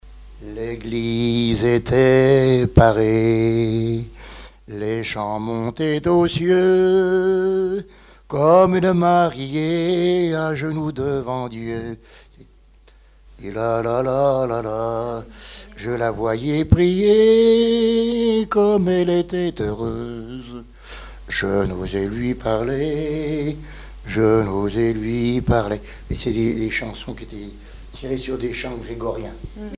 circonstance : fiançaille, noce ;
Genre strophique
interprétation de chansons traditionnelles et populaires